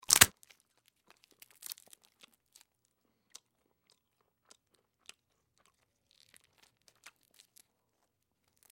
Chewing
Break Shell And Chew With Lip Smack And Close Bite, X4